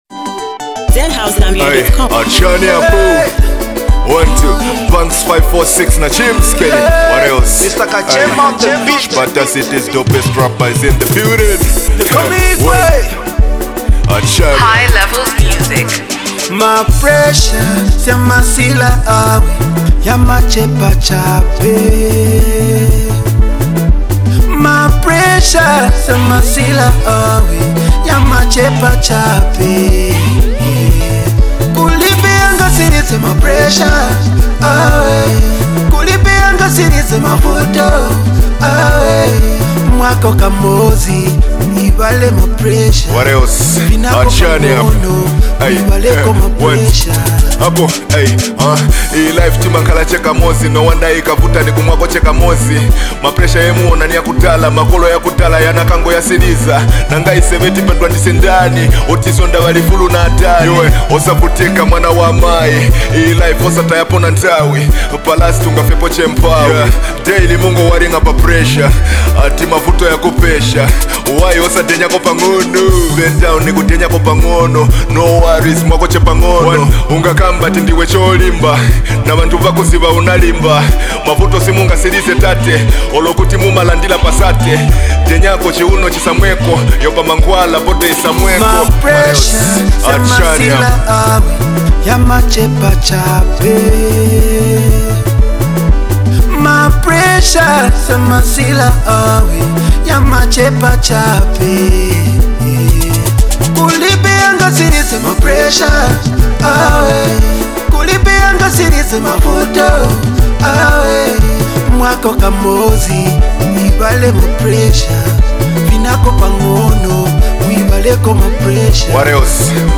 a powerful jam that speaks real life facts